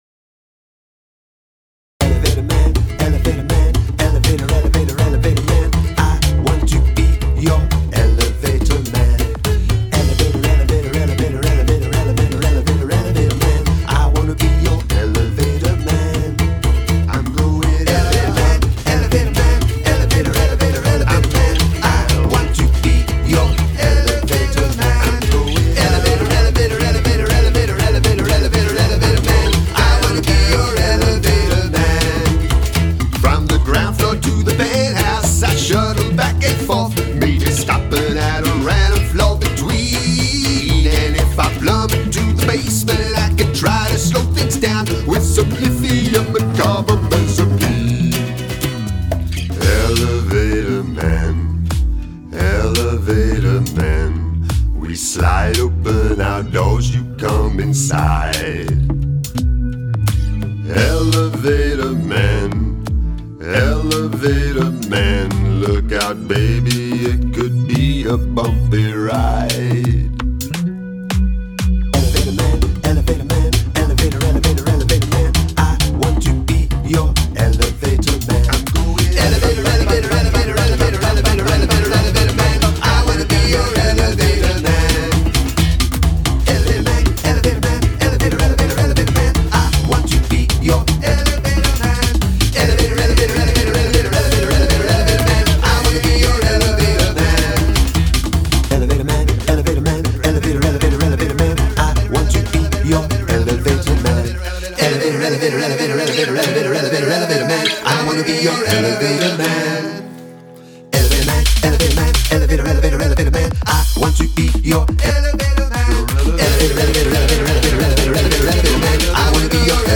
Prominent musical repetition